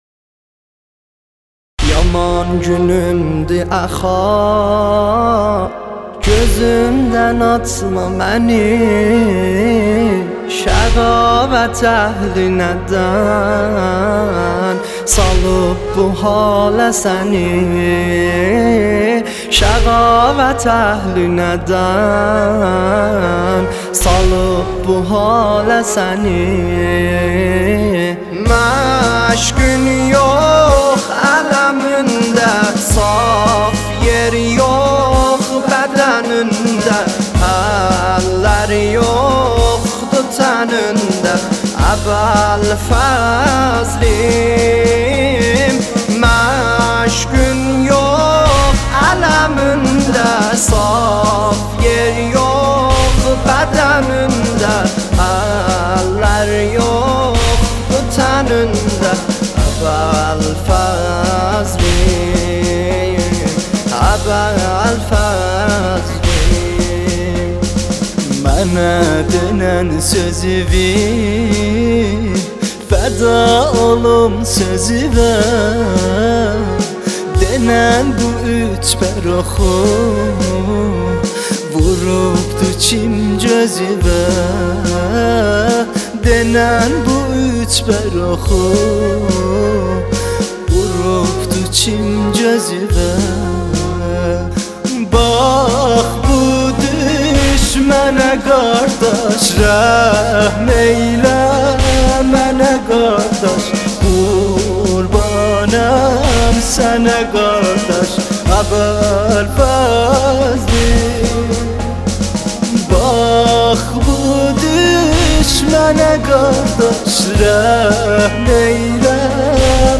دسته بندی : نوحه ترکی تاریخ : شنبه 31 آگوست 2019